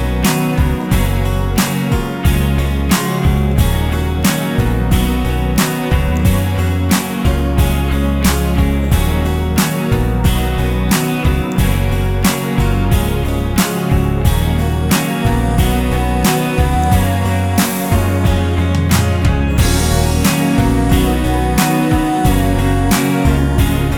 Reduced Backing Vocals R'n'B / Hip Hop 4:13 Buy £1.50